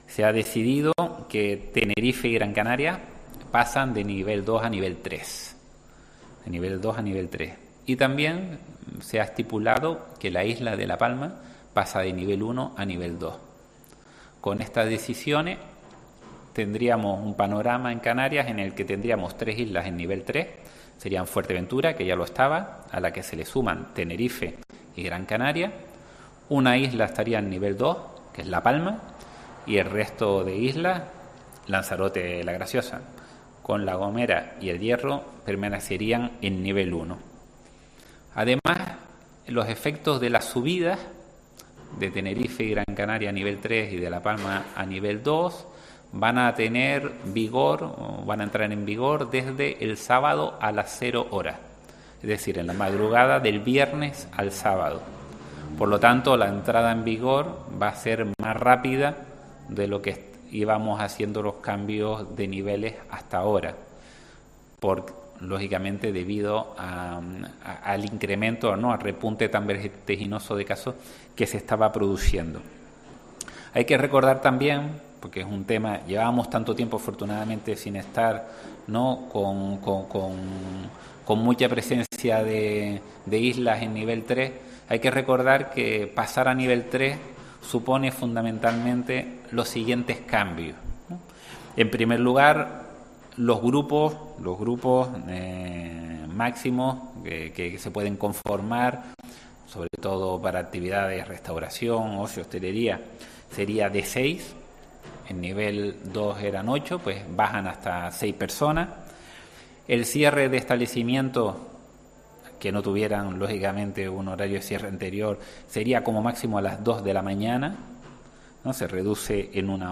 Antonio Olivera, viceconsejero de Presidencia del Gobierno de Canarias